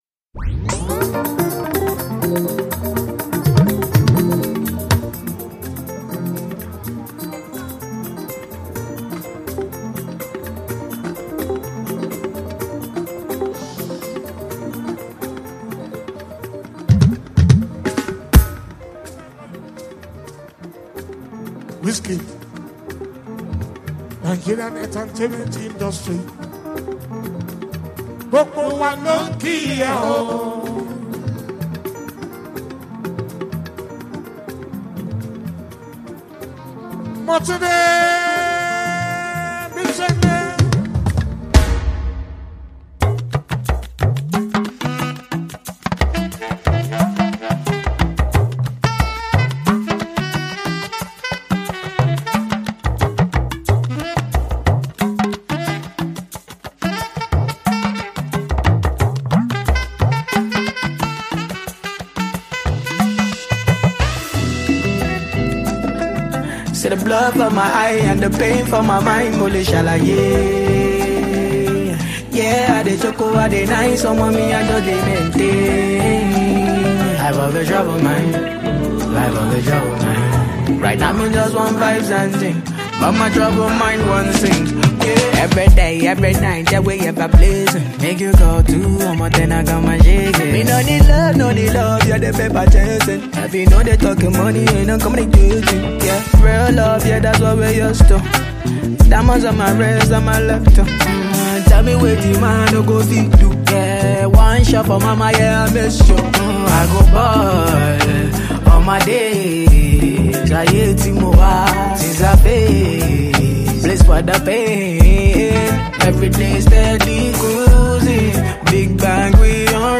Grammy-winning Nigerian singer-songwriter